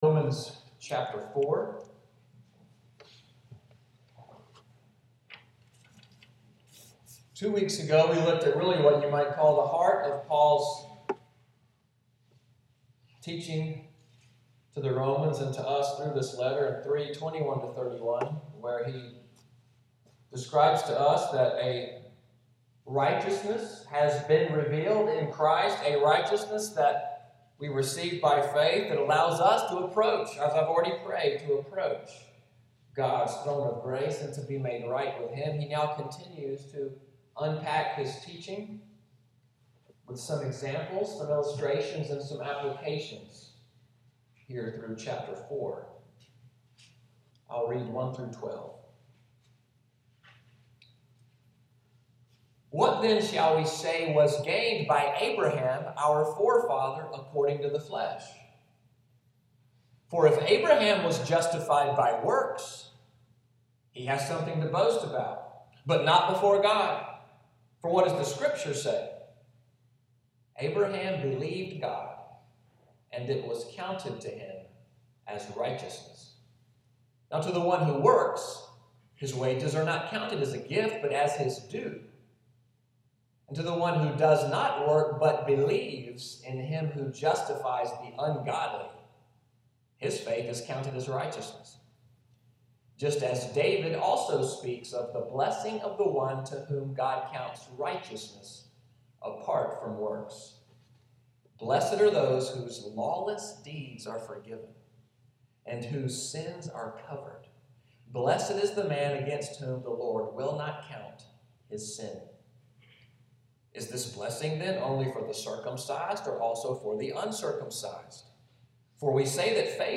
Sunday’s sermon, “The Glorious Reckoning,” March 15, 2015